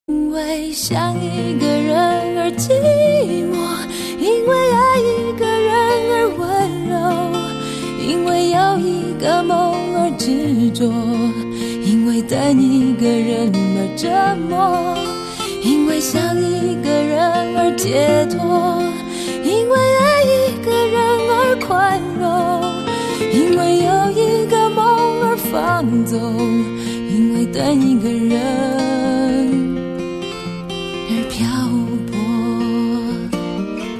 M4R铃声, MP3铃声, 华语歌曲 129 首发日期：2018-05-15 22:48 星期二